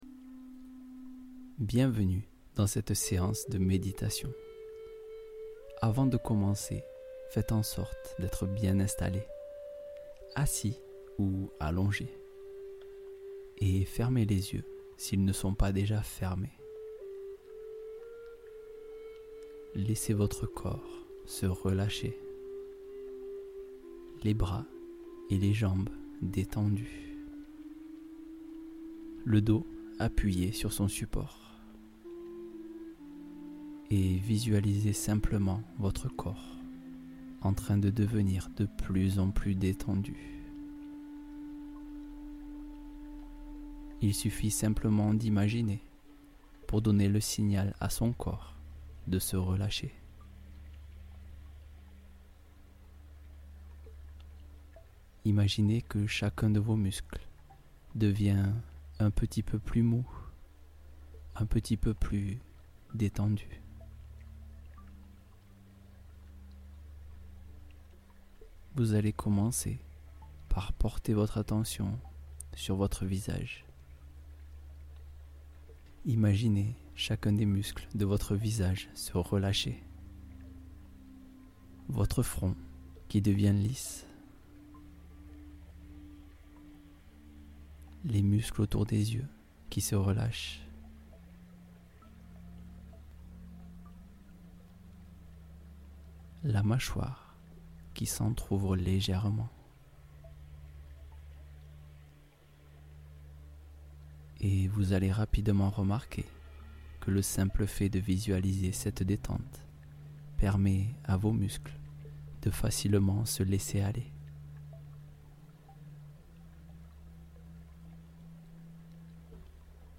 Réveillez votre force intérieure en 15 minutes : la méditation matinale qui transforme vos journées